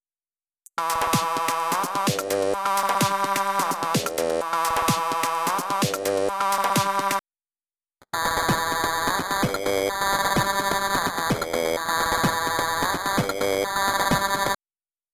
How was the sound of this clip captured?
OK but that plays it at the correct speed, but it has nothing above 4kHz. It doesn’t have the high-frequency digital-artefacts, which was the brief.